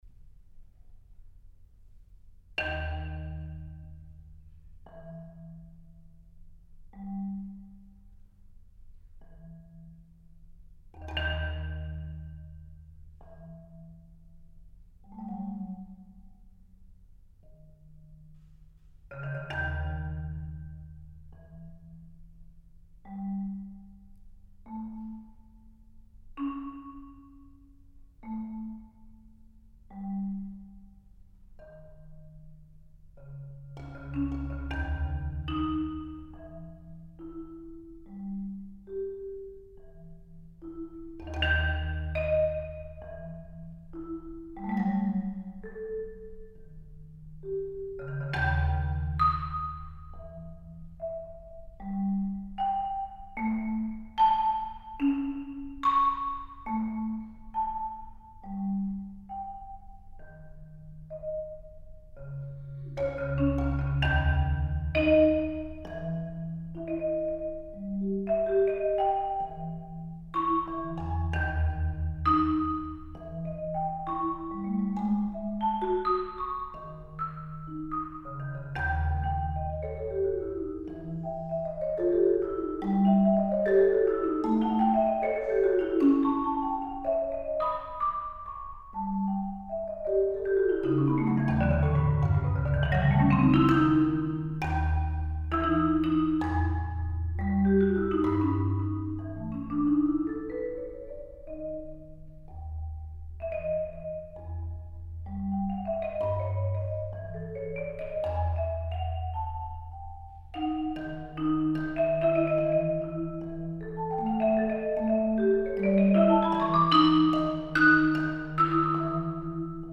Genre: Marimba (4-mallet) + Track
Marimba (5-octave)